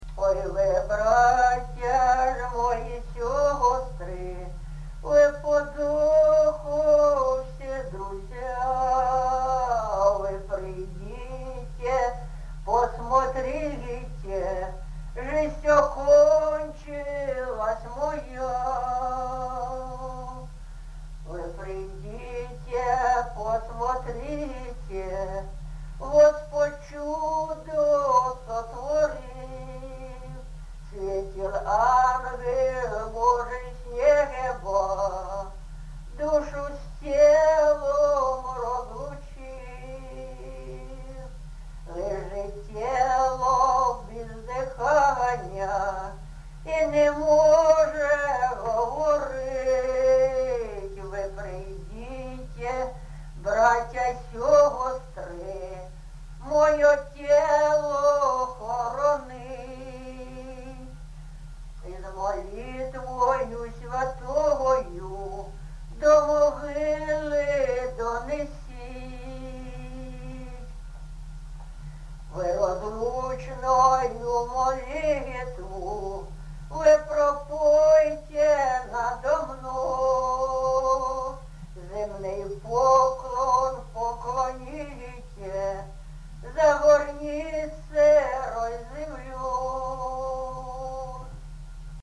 ЖанрПсальми
Місце записус. Софіївка, Краматорський район, Донецька обл., Україна, Слобожанщина